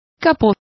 Complete with pronunciation of the translation of bonnet.